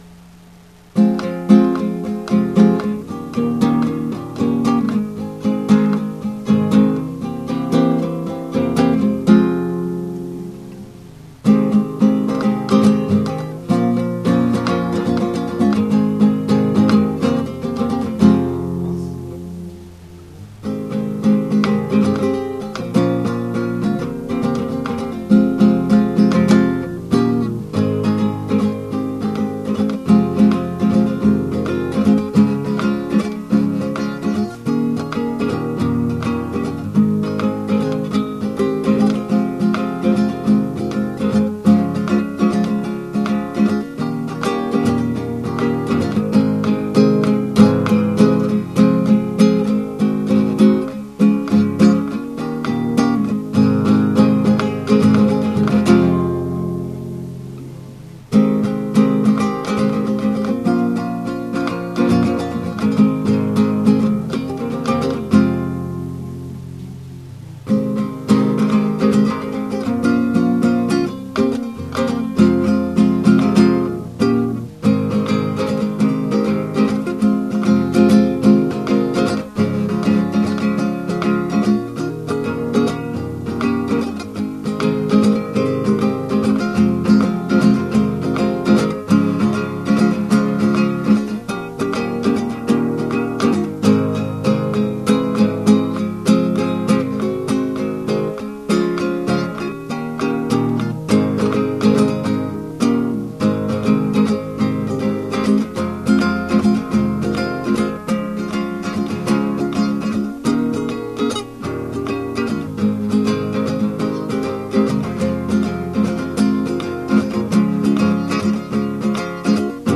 カラオケ